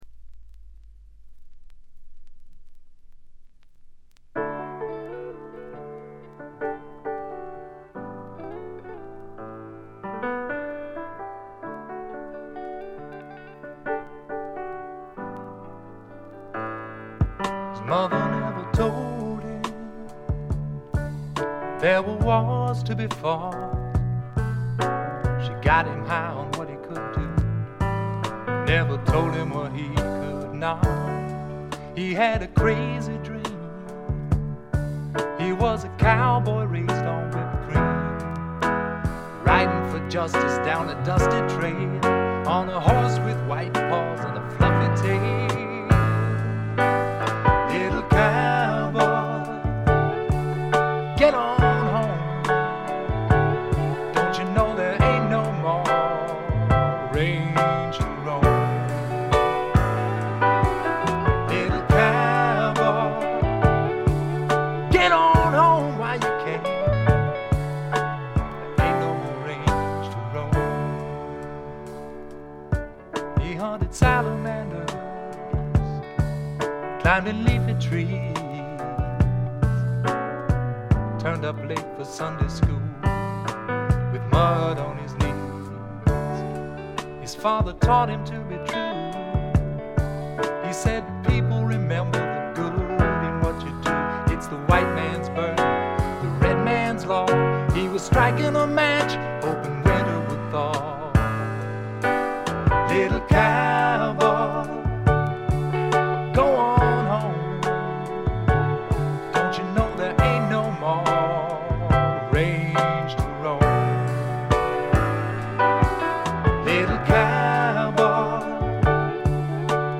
ごくわずかなノイズ感のみ。
シンプルなバックに支えられて、おだやかなヴォーカルと佳曲が並ぶ理想的なアルバム。
試聴曲は現品からの取り込み音源です。